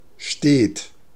Ääntäminen
IPA : /kənˈtɪnjuəs/